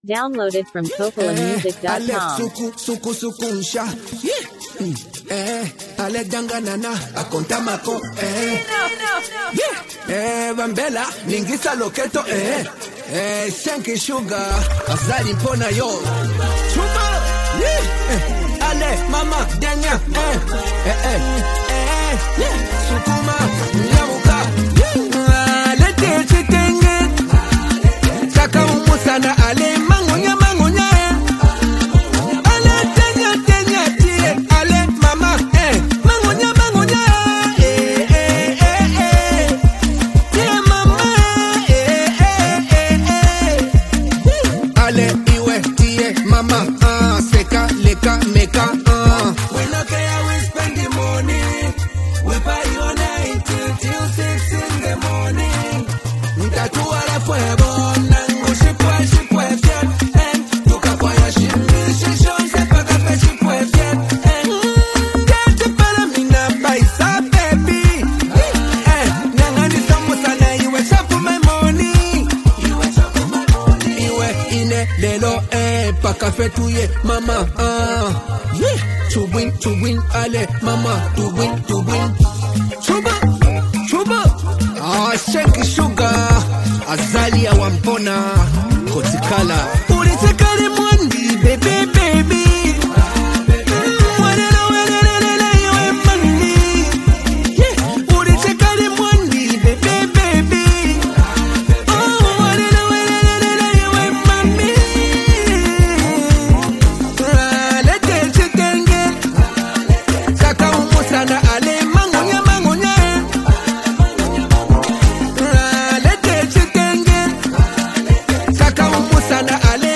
playful yet meaningful Zambian song